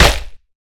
peachHit1.wav